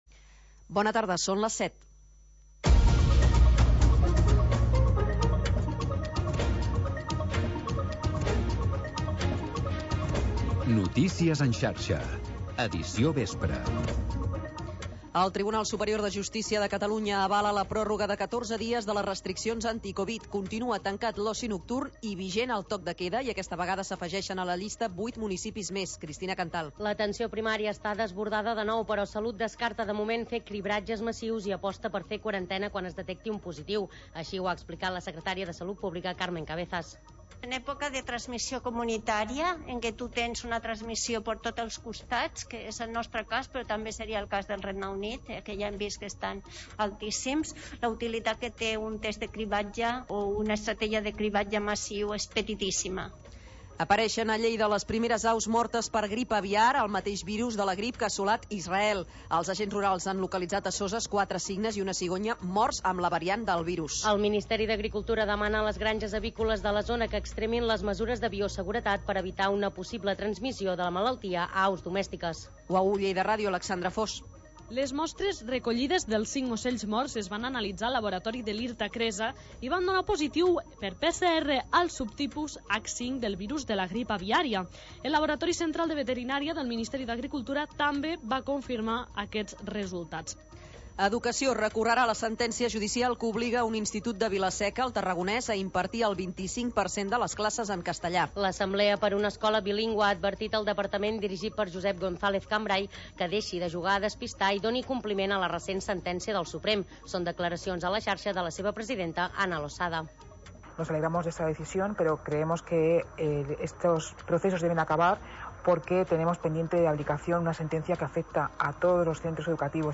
Noticiari d'informació territorial